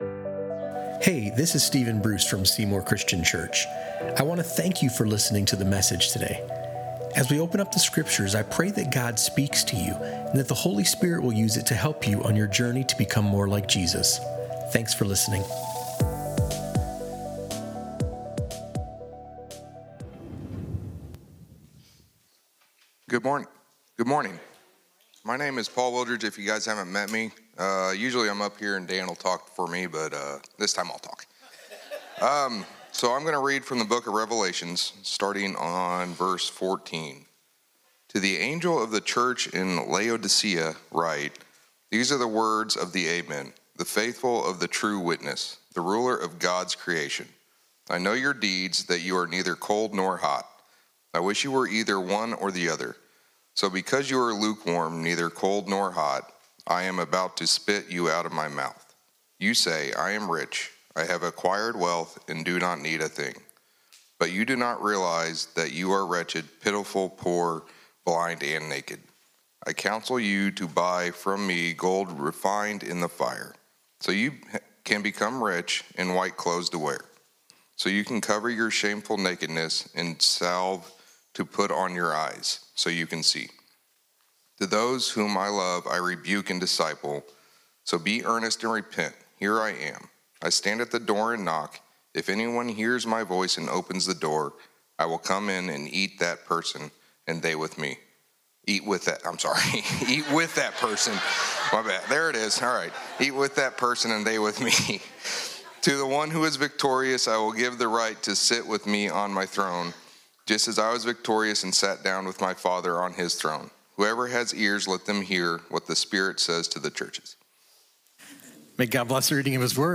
Learn why lukewarm faith is so dangerous, how comfort and self-reliance create an illusion of independence from God, and how to open the door to a deeper relationship with Christ. A timely sermon for anyone longing for renewed passion, purpose, and dependence on Jesus.